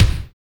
TRASHY KICK.wav